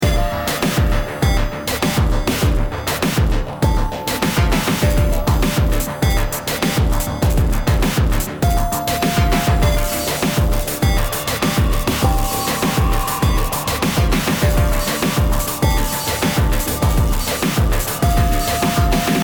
528hz BPM100-109 Game Instrument Soundtrack インストルメント
BPM 100